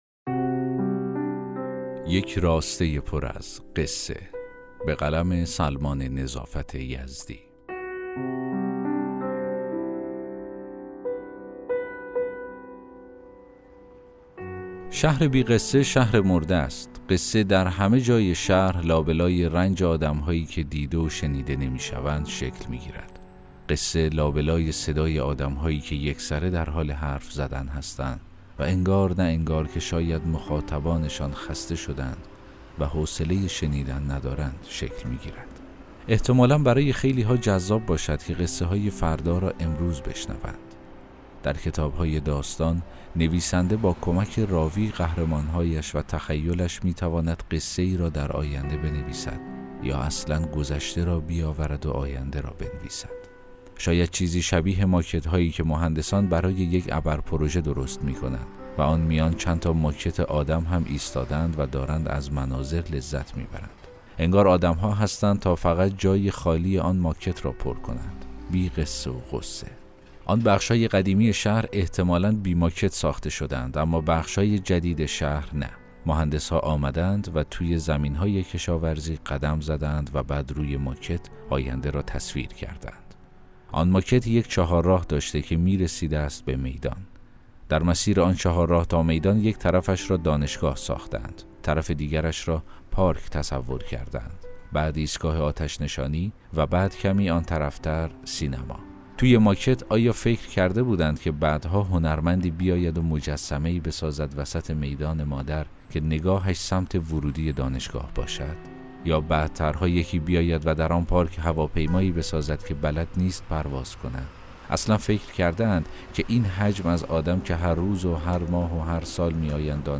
داستان صوتی: یک راسته پر از قصه